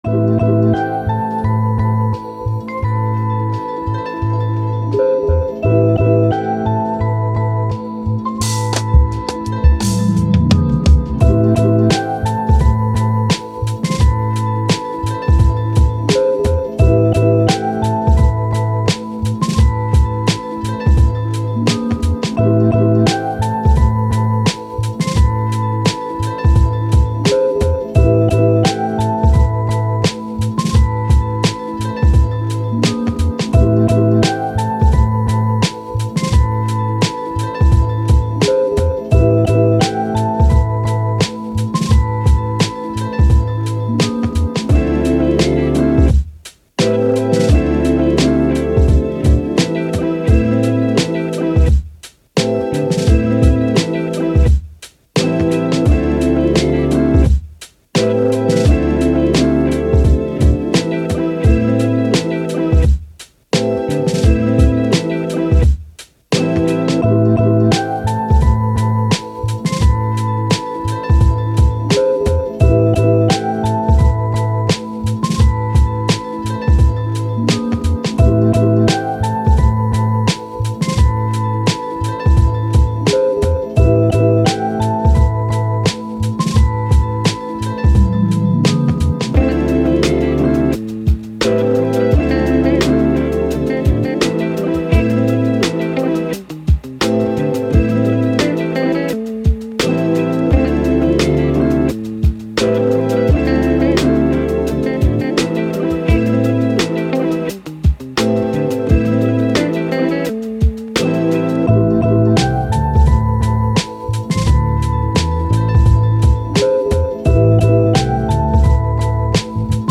Hip Hop, Mix, Positive, Chilled